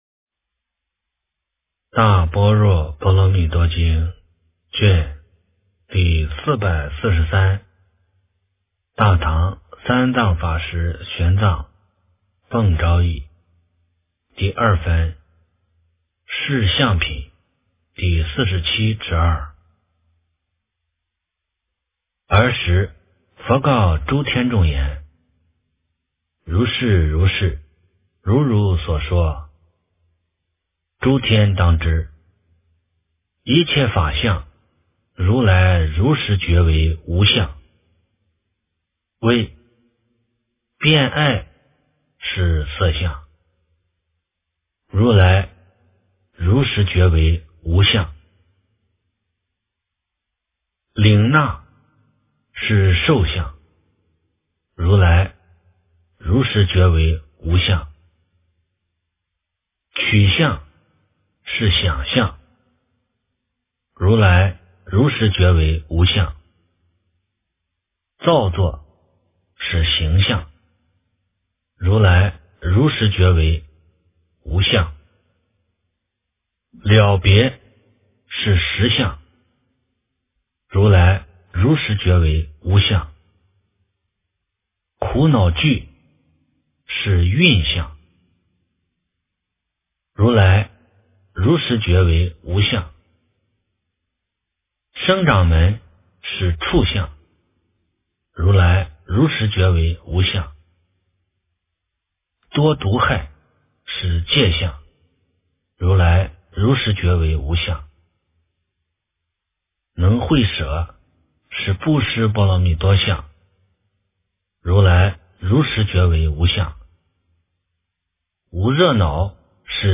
大般若波罗蜜多经第443卷 - 诵经 - 云佛论坛